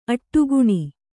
♪ aṭṭuguṇi